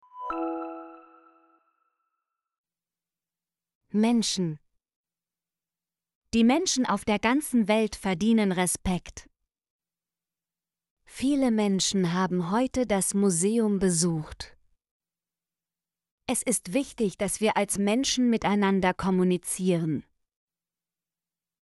menschen - Example Sentences & Pronunciation, German Frequency List